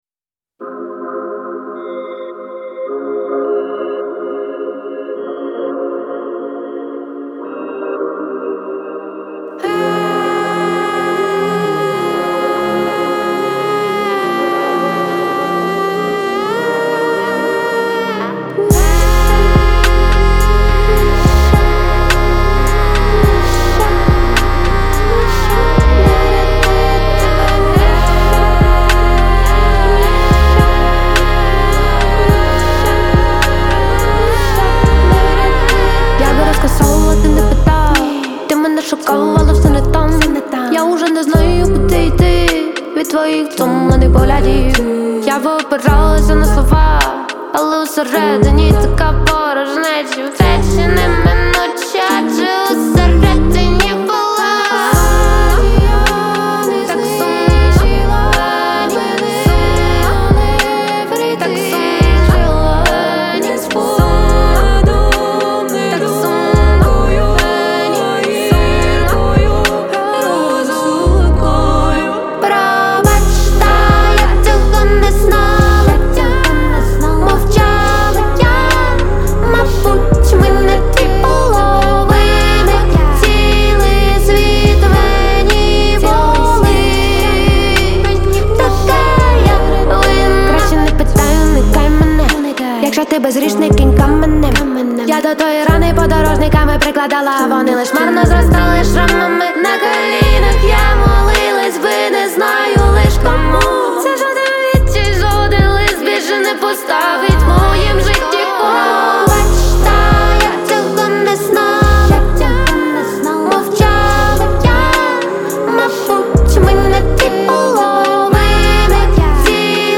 • Жанр: Pop, Indie